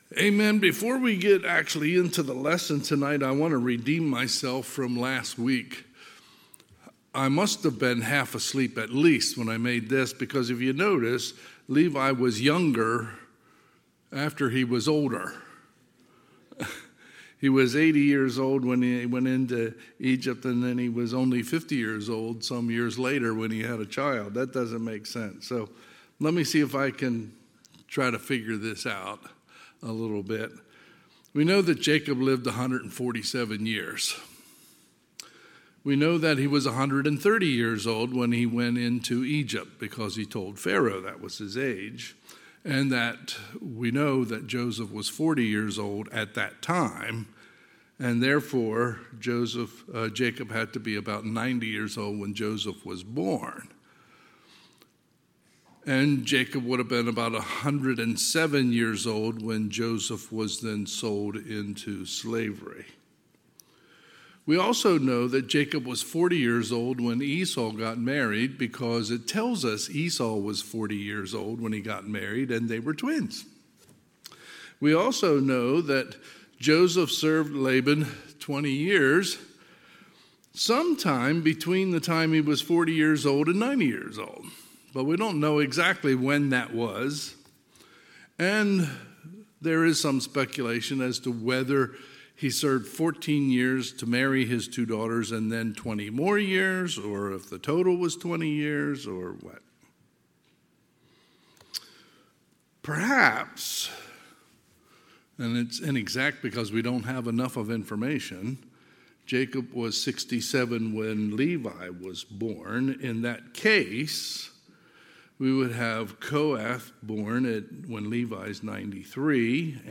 Sunday, July 2, 2023 – Sunday PM